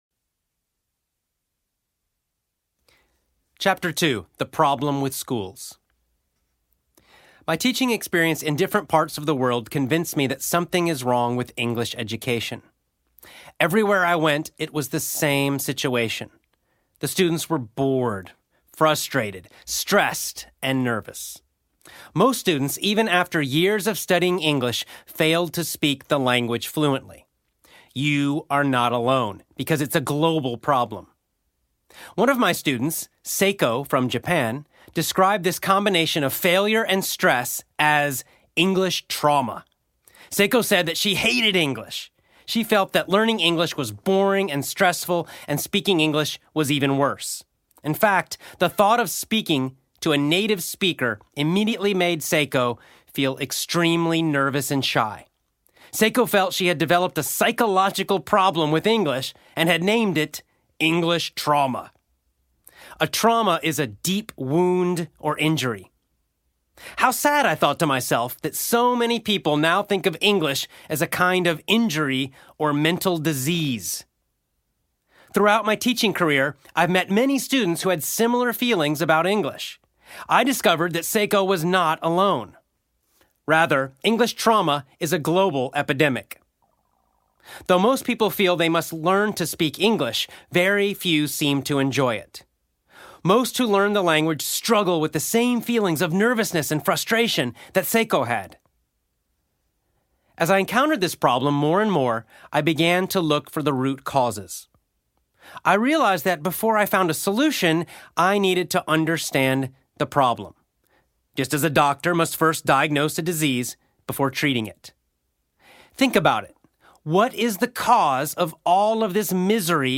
EE AUDIOBOOK Chapter 02 The Problem with Schools
EE-Audiobook-02-The-Problem-With-Schools.mp3